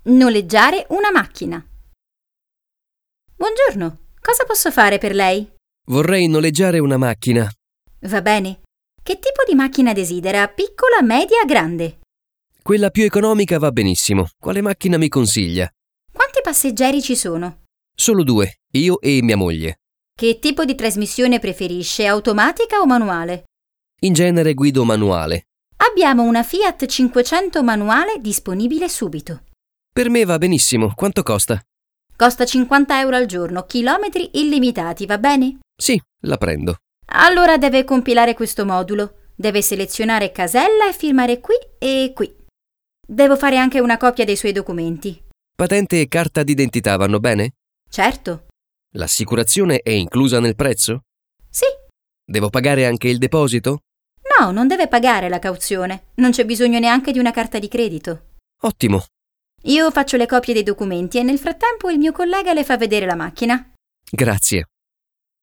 HASZNOS PÁRBESZÉD: Noleggiare una macchina – Autóbérlés